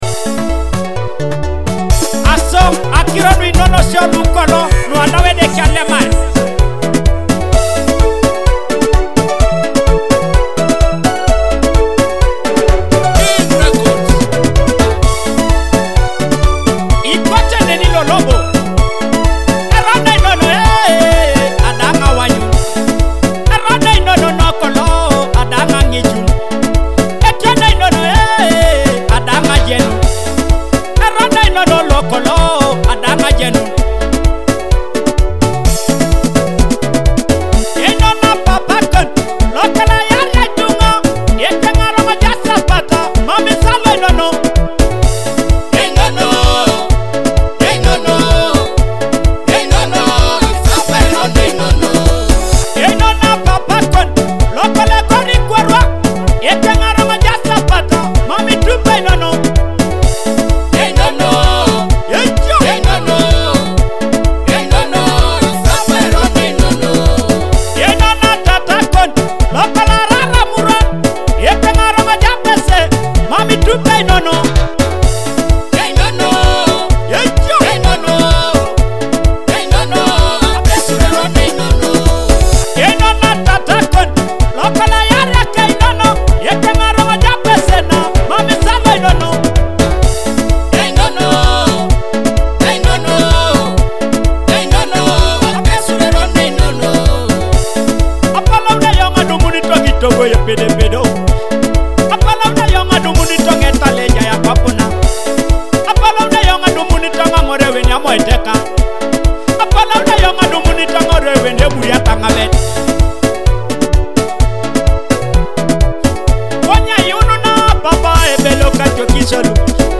Teso cultural and traditional rhythms